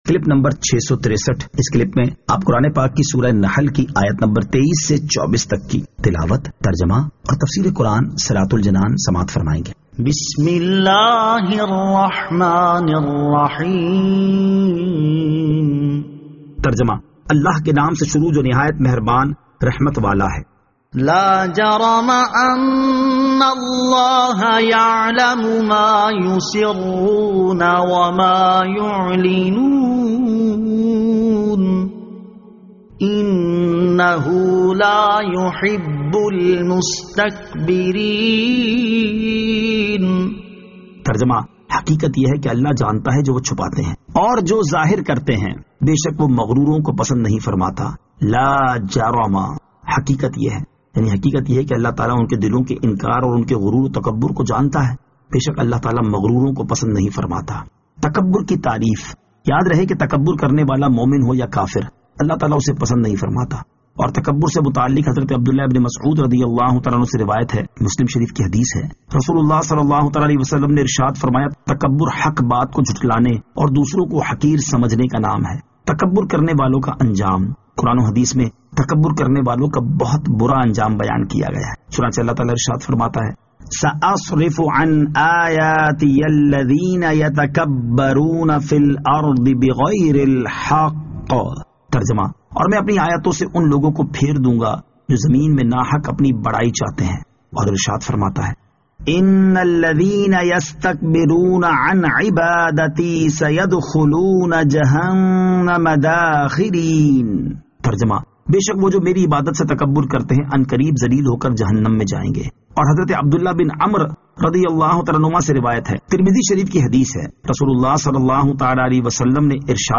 Surah An-Nahl Ayat 23 To 24 Tilawat , Tarjama , Tafseer